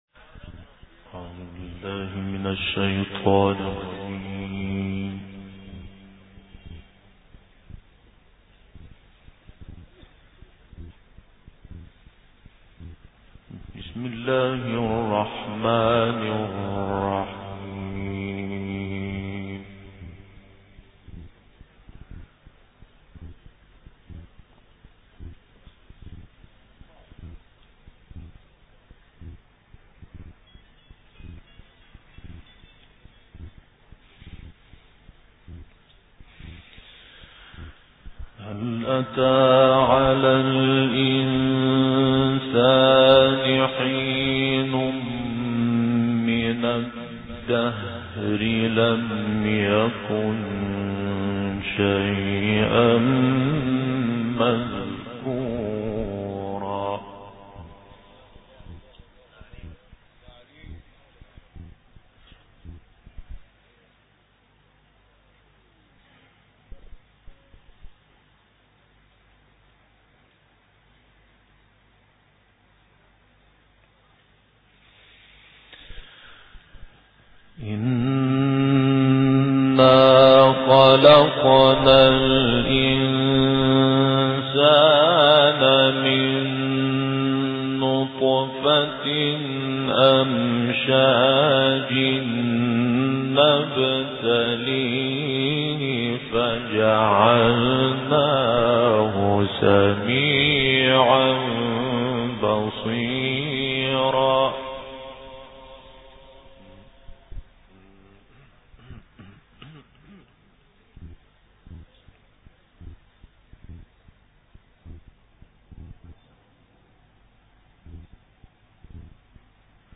Quran recitations